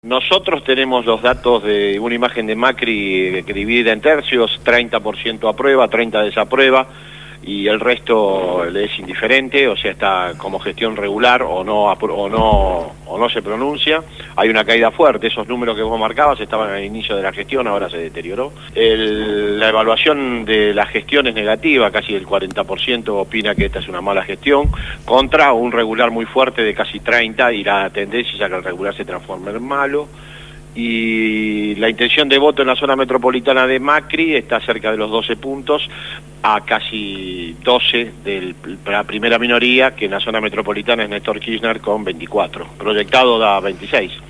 Verborrágico, locuaz.